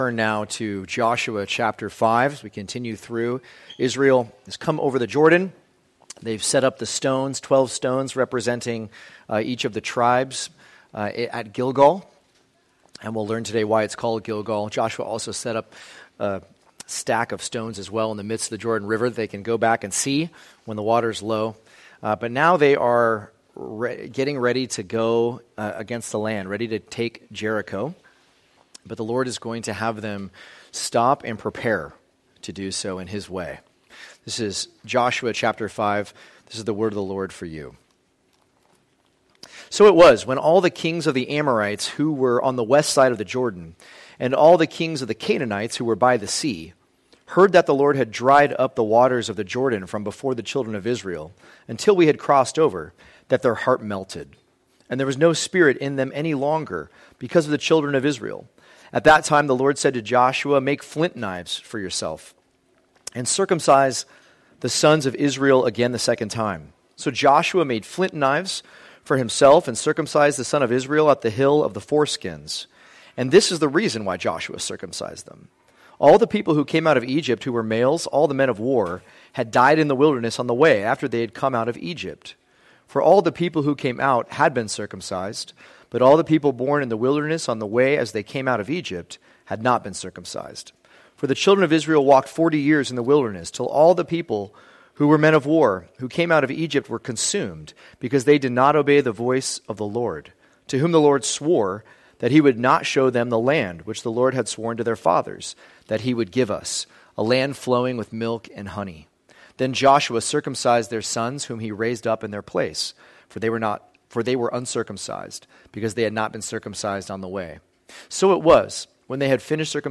2024 Preparing to Prosper Preacher